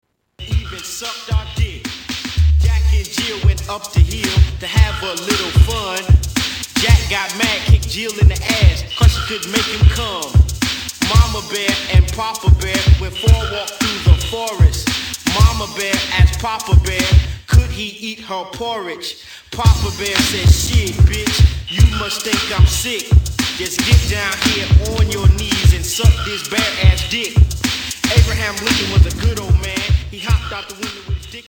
Rap
Hip Hop